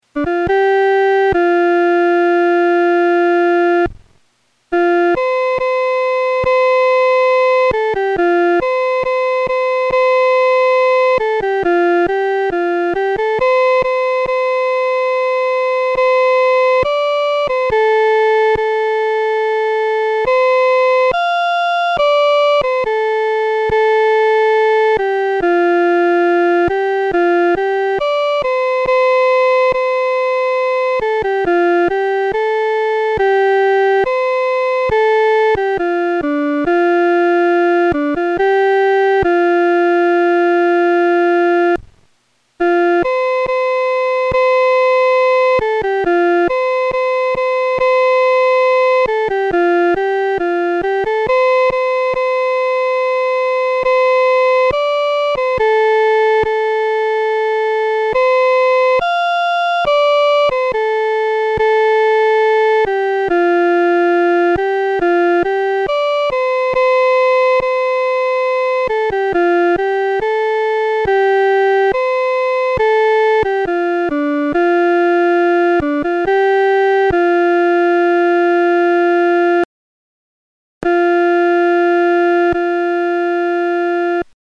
伴奏
女高
本首圣诗由网上圣诗班 （南京）录制
此曲主要刻划梅花洁白、清新的形象，曲调活泼，节奏明快，故改编者选择用它为《诗篇》第100篇所用。